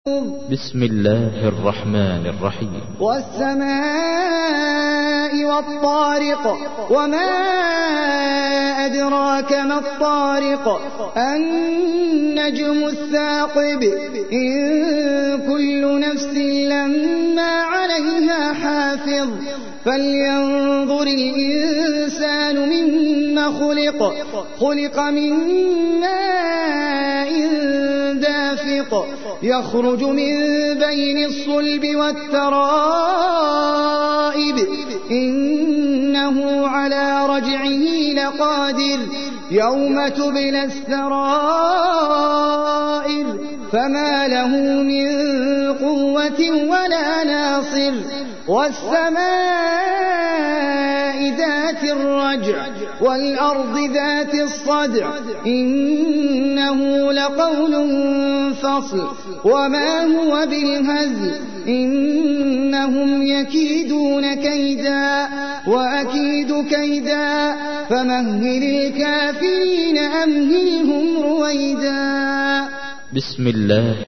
تحميل : 86. سورة الطارق / القارئ احمد العجمي / القرآن الكريم / موقع يا حسين